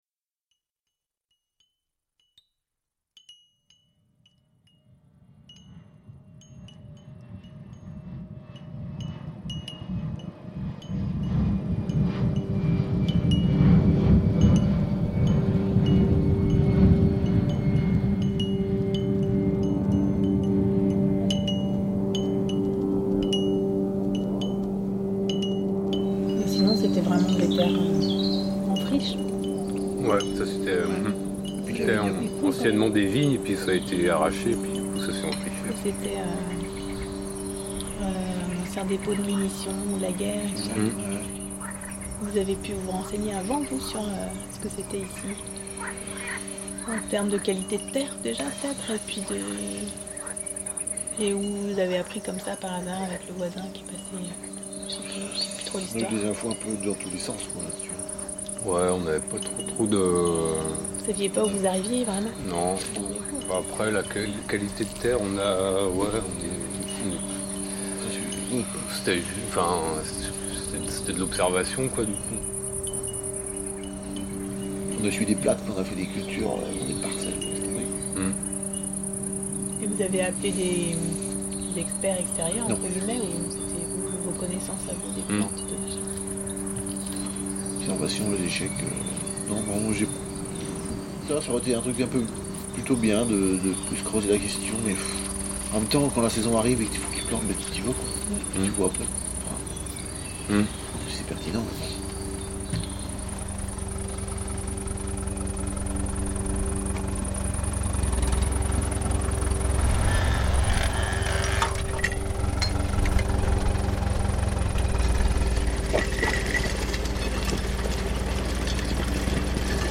Table des matières SALON D'ÉCOUTE TRACTICOSME FORDLANDIA SALON D'ÉCOUTE FLYER : Les fauteuils double sont le dispositif de diffusion de deux œuvres de poésie sonore : Forlandia et Tracticosme.